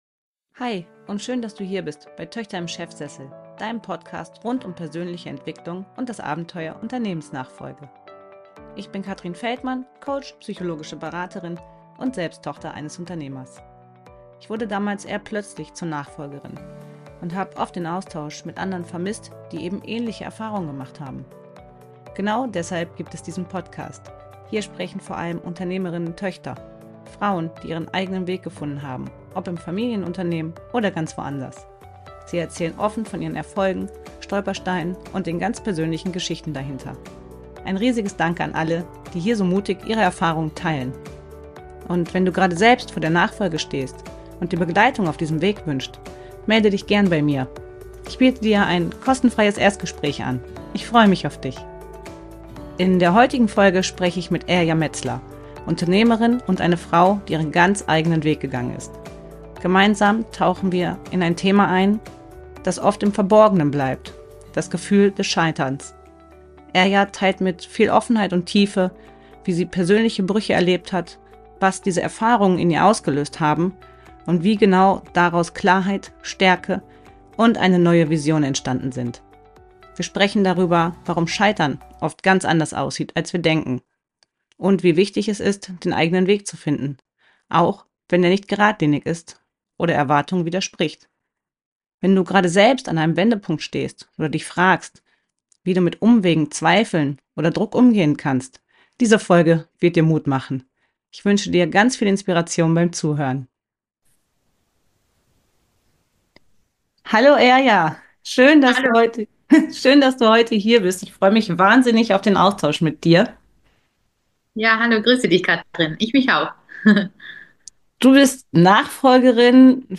Als der Plan zerbrach, begann ihr Weg – Interview